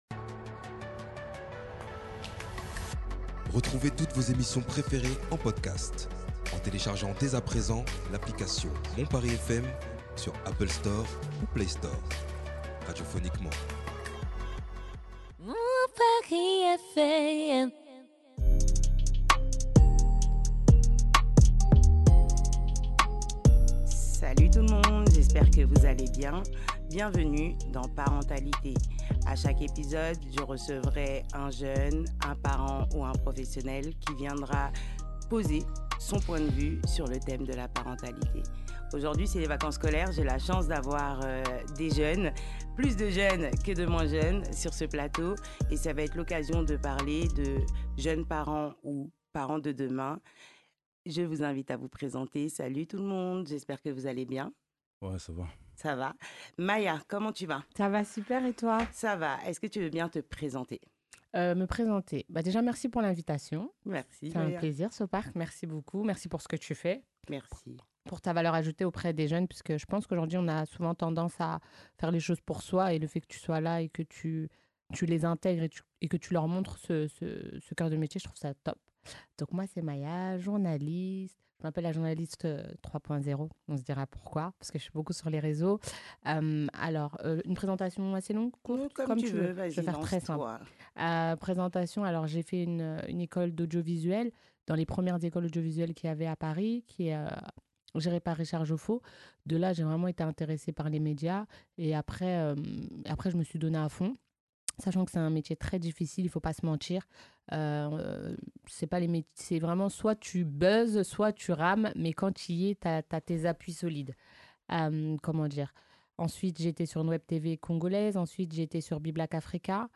Pour cette rencontre, nous avons la chance de recevoir quatre invités avec des profils variés et des visions uniques sur la parentalité.